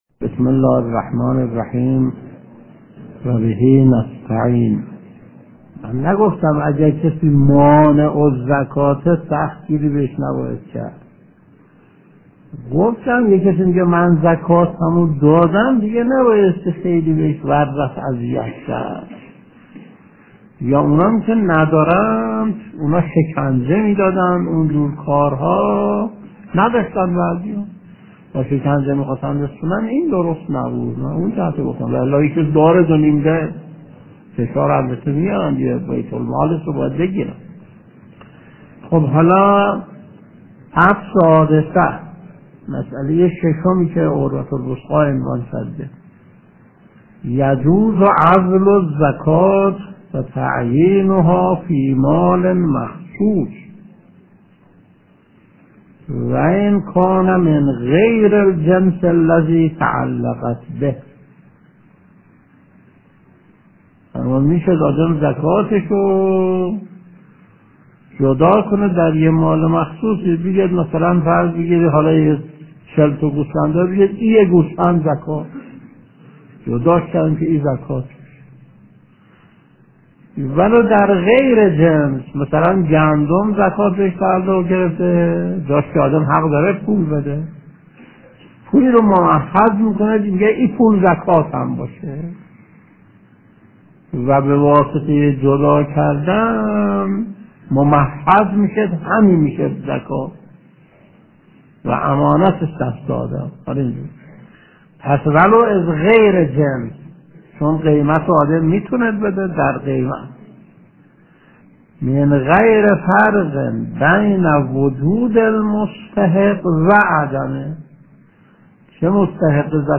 درس 554 : (16/11/1369)